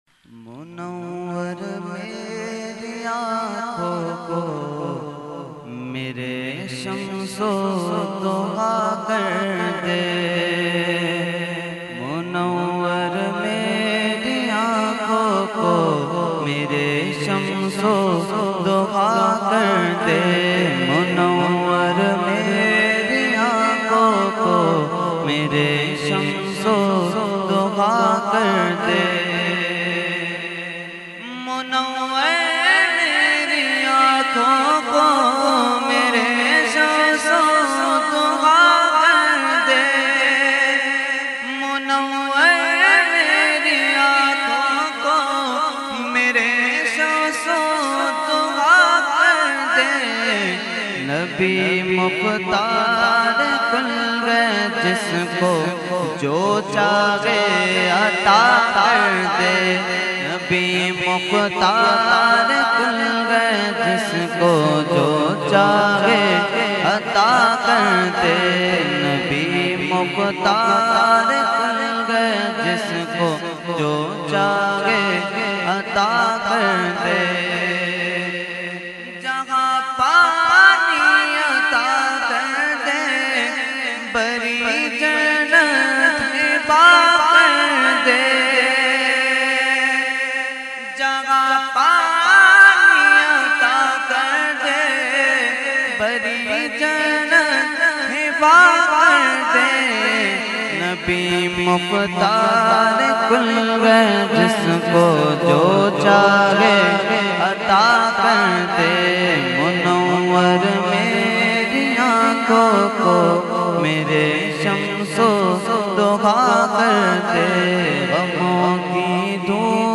Mediaa: Muharram 2022 9 Roza Mehfil e Muharram ul Haram held on 1st Muharram ul Haram to 9th Muharram ul Haram at Jamia Masjid Ameer Hamza Nazimabad Karachi.
Category : Naat | Language : UrduEvent : Muharram 2022